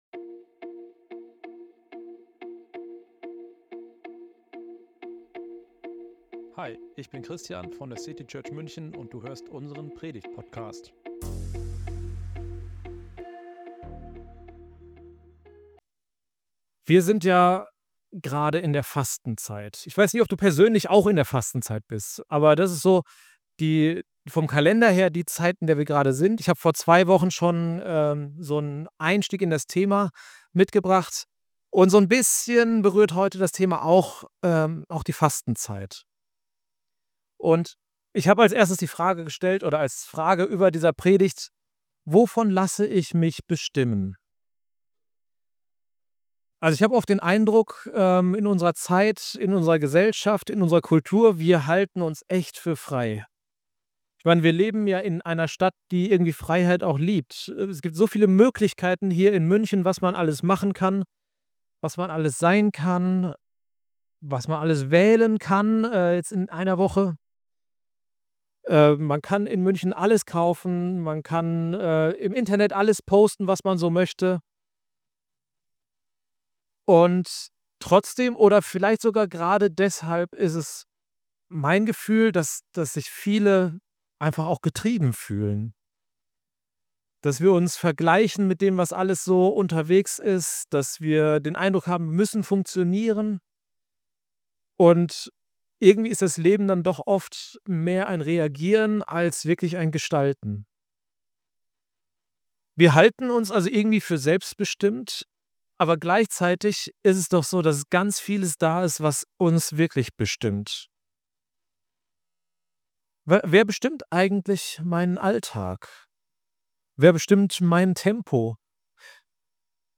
Eine Predigt über Freiheit, Prägung – und die Frage: Wovon lasse ich mich bestimmen?